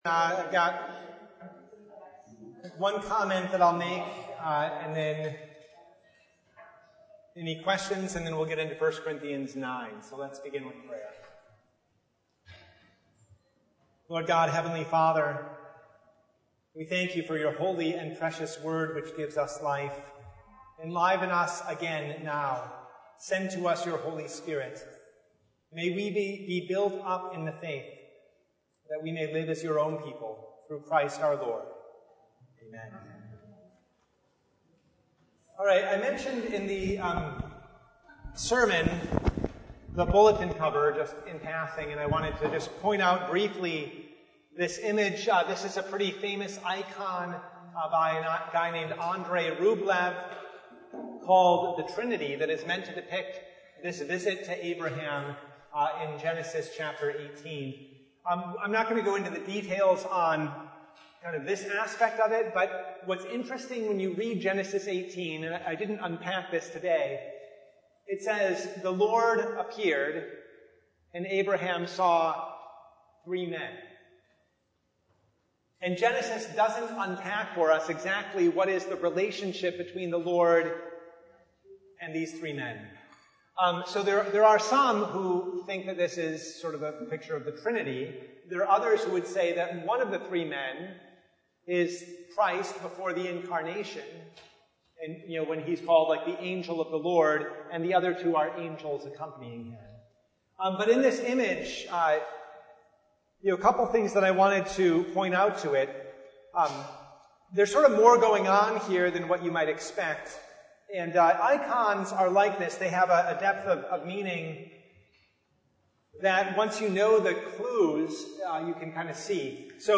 1 Corinthians 9:8-23 Service Type: Bible Hour Topics: Bible Study